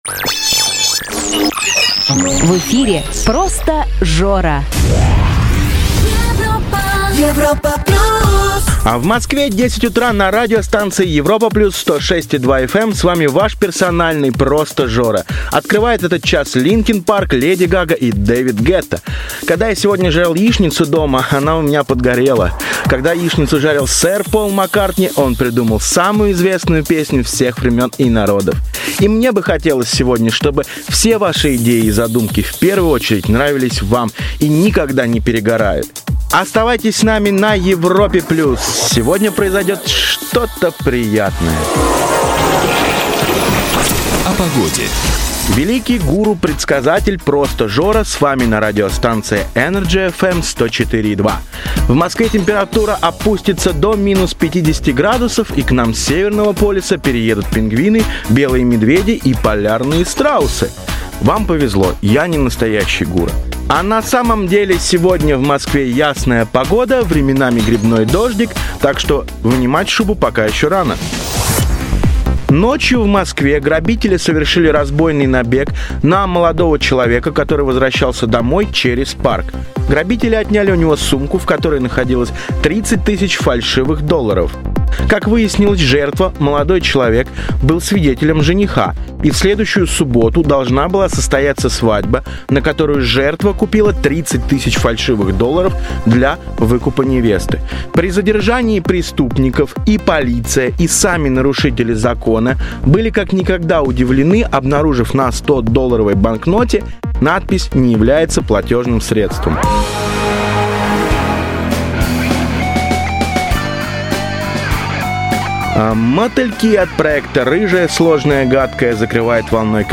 демо голос.mp3
Мужской
Баритон Бас